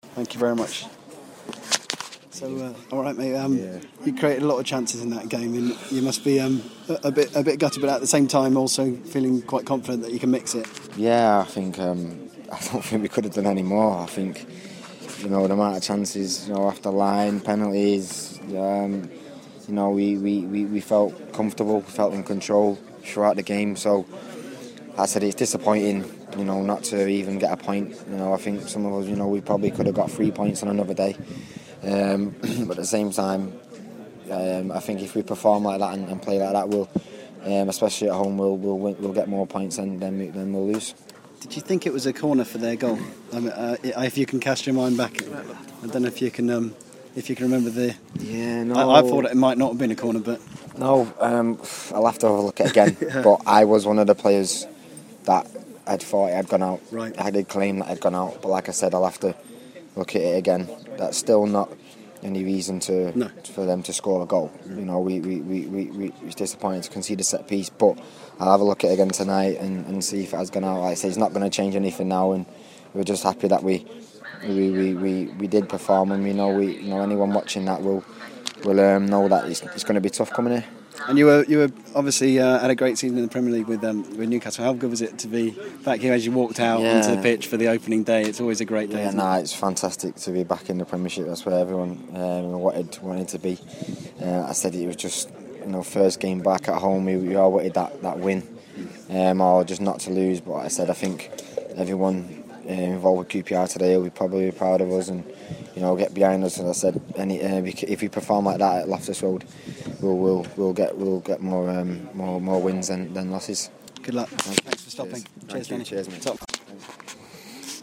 QPR defender Danny Simpson following his teams defeat by Hull City